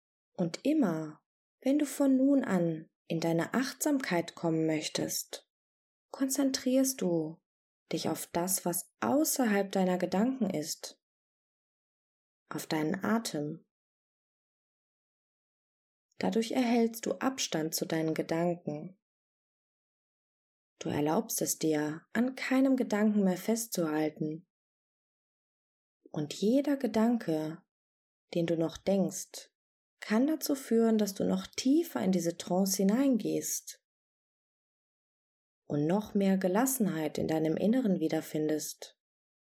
Genießen Sie mit dieser geführten Hypnose eine willkommene Entspannung vom Alltag und seinen Sorgen und nehmen Sie diese Befreiung mit in Ihr Leben.
Sprecherin